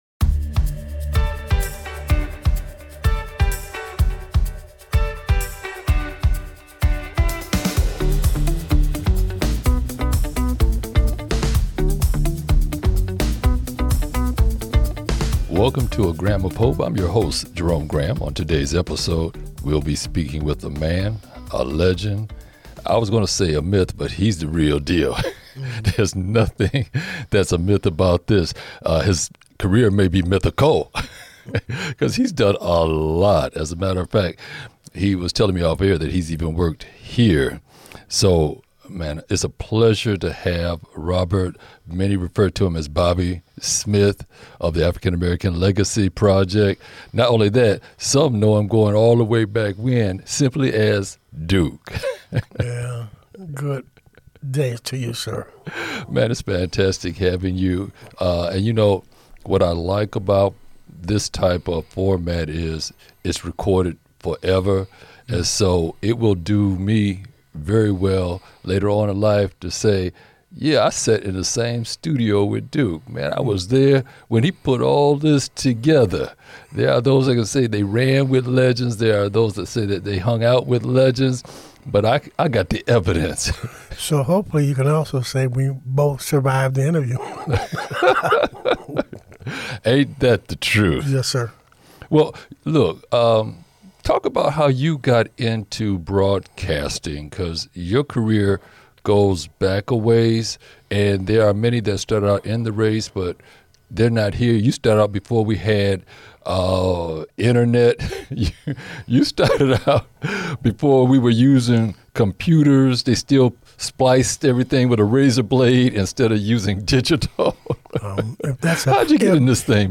This podcast conversation is just a snippet of those contributions.